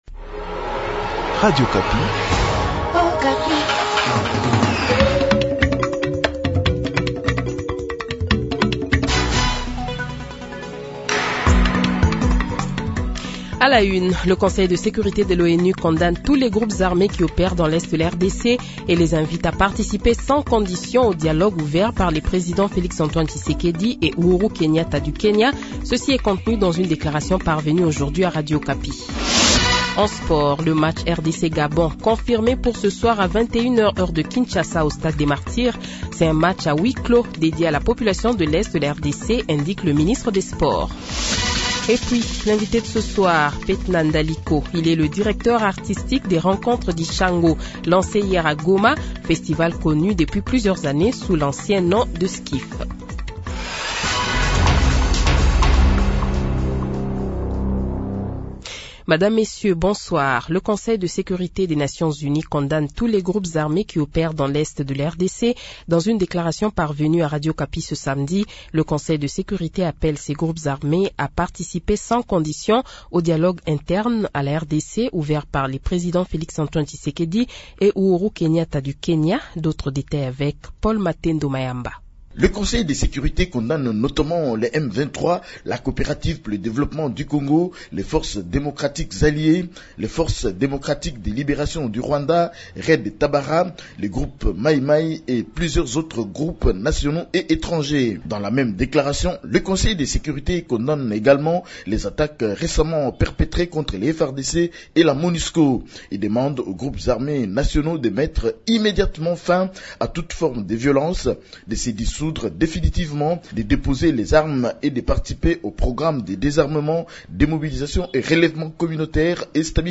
Journal Soir
Le Journal de 18h, 04 Juin 2022 :